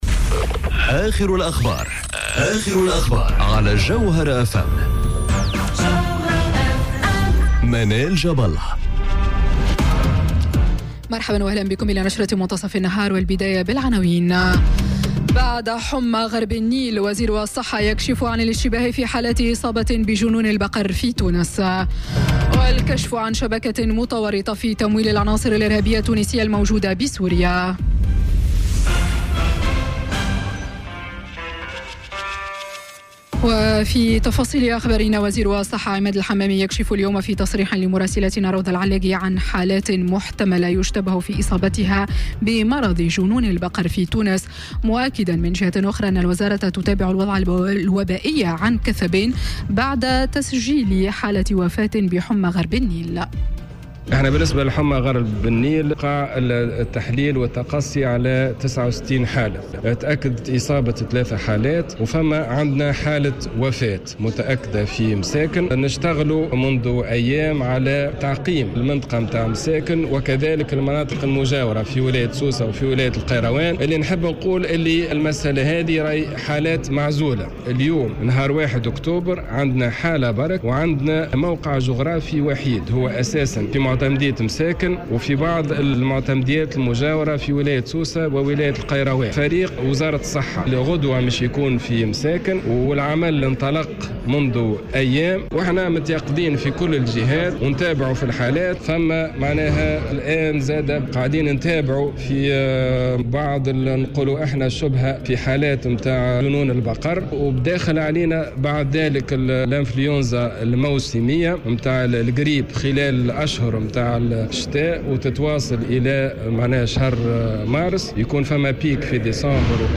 نشرة أخبار منتصف النهار ليوم الإثنين 01 أكتوبر 2018